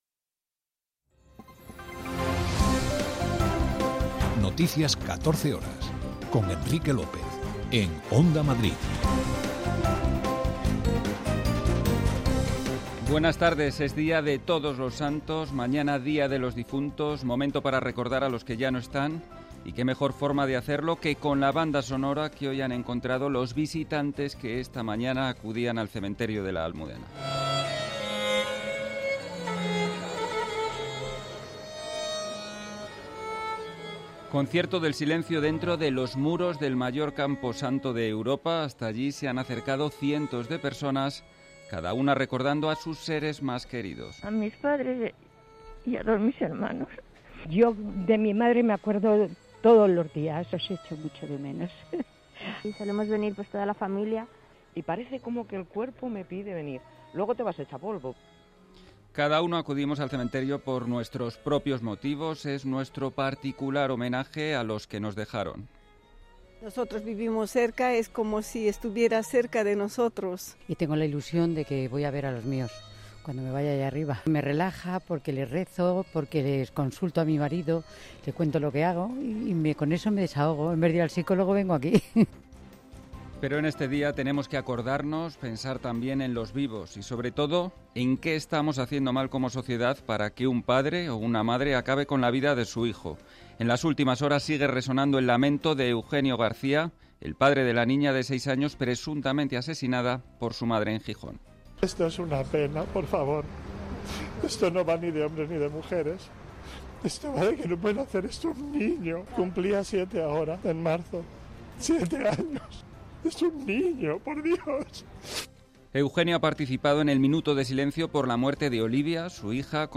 Noticias 14 horas 01.11.2022